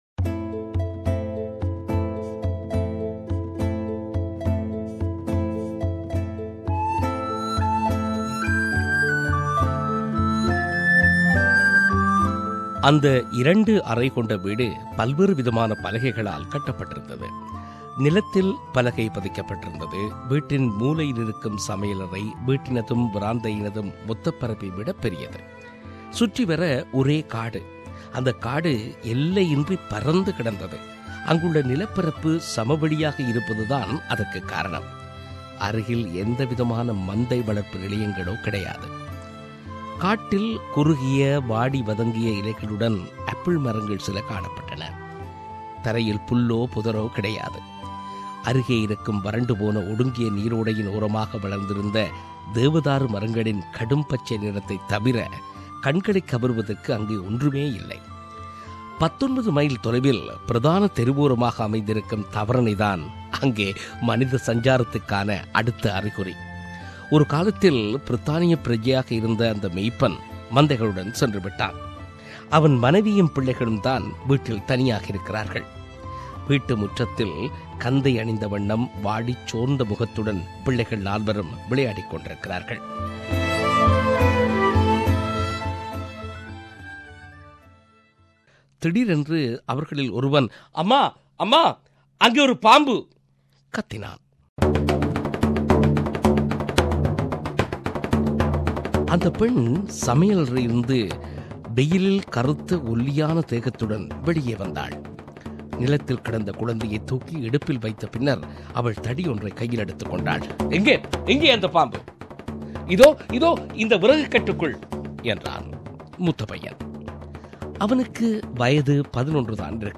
அந்தச் சிறுகதைகளில் "The Drover's wife" எனும் சிறுகதையை நாம் இங்கே ஒலிவடிவில் தருகிறோம்.